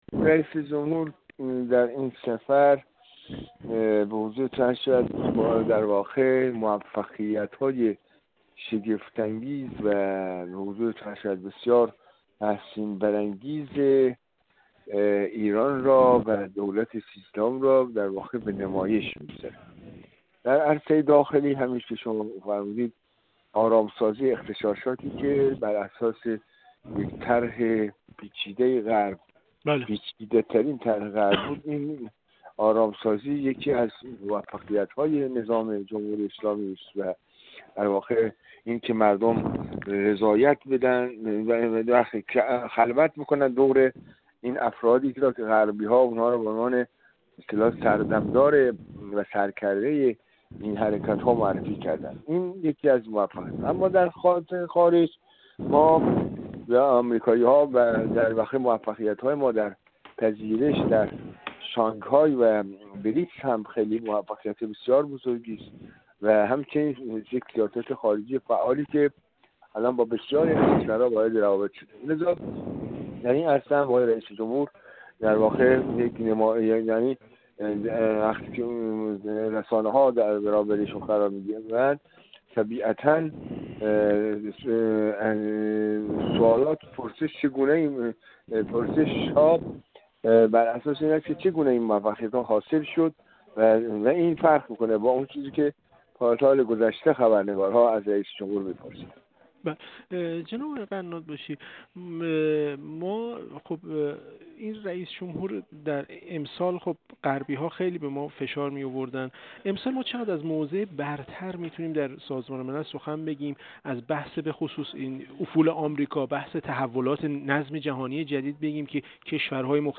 کارشناس مسائل غرب آسیا
گفت‌وگو